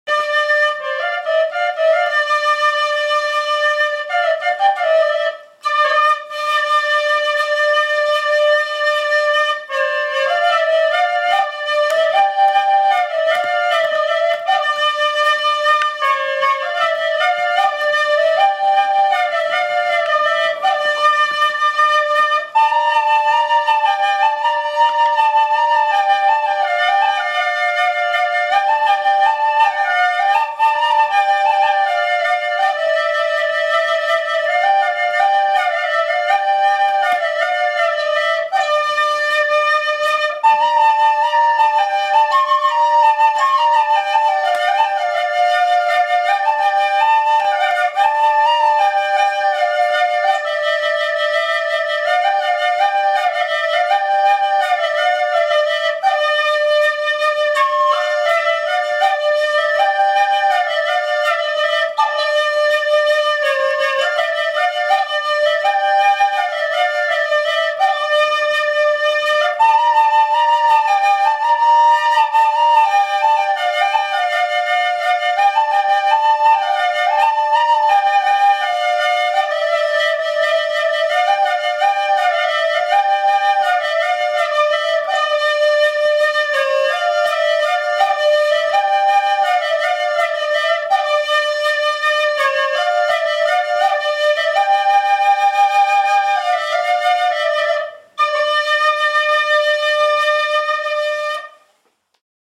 موسیقی سیستان و بلوچستان